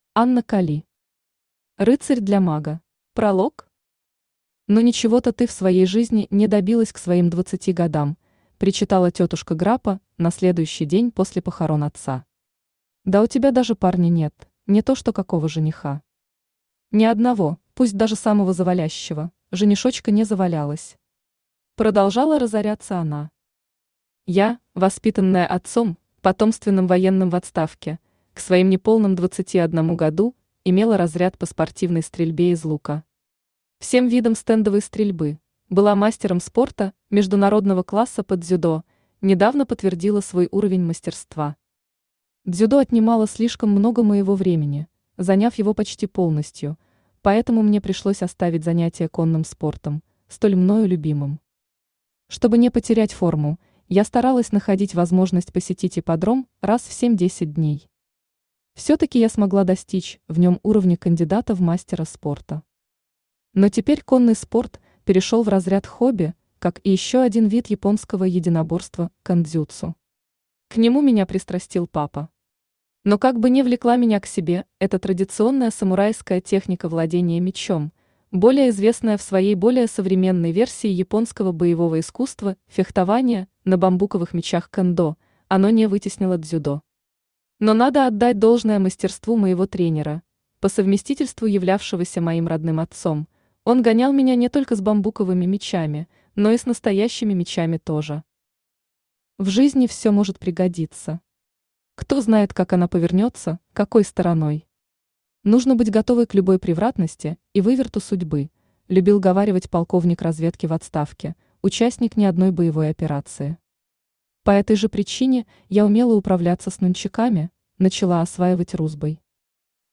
Аудиокнига Рыцарь для мага | Библиотека аудиокниг
Aудиокнига Рыцарь для мага Автор Анна Кали Читает аудиокнигу Авточтец ЛитРес.